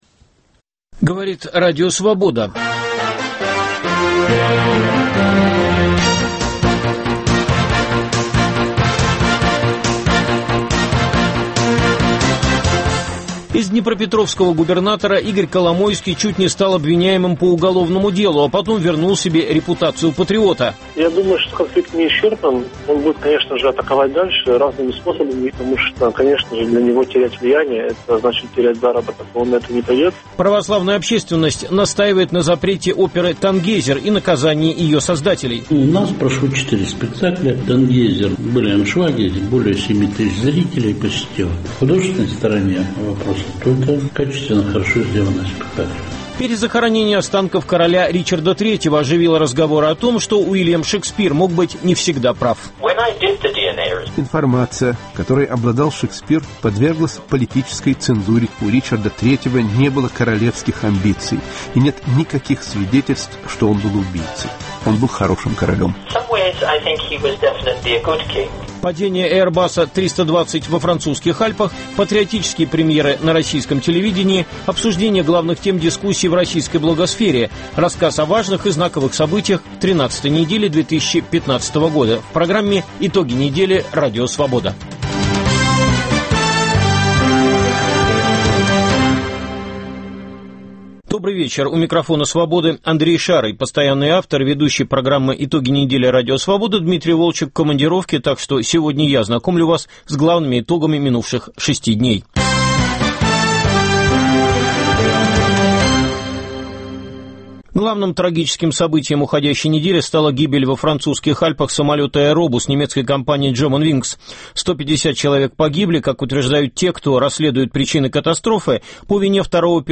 Итоги недели в России и в мире подводит в прямом эфире